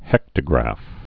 (hĕktə-grăf)